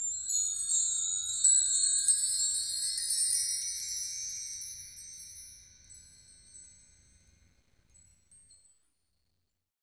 Index of /90_sSampleCDs/Roland LCDP03 Orchestral Perc/PRC_Wind Chimes2/PRC_Marktree